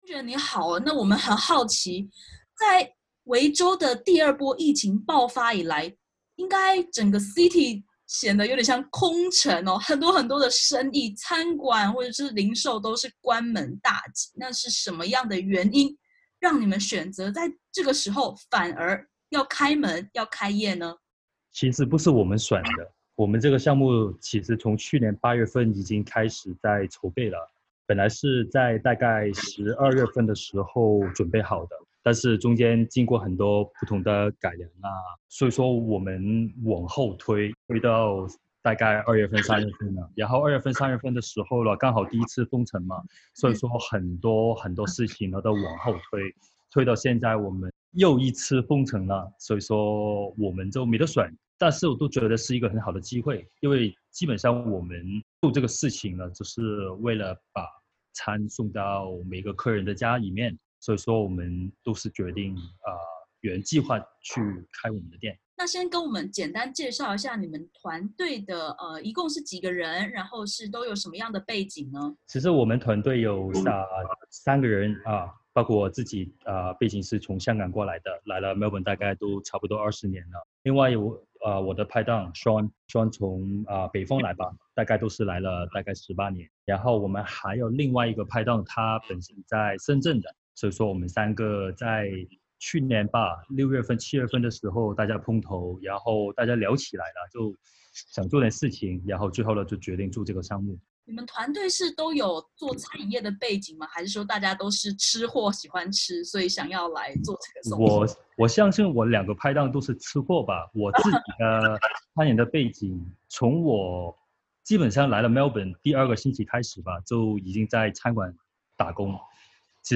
维州进入4级封锁，许多餐饮业关门或歇业，却有一支团队在此时挺进，危机就是转机吗？点击图片收听完整采访音频。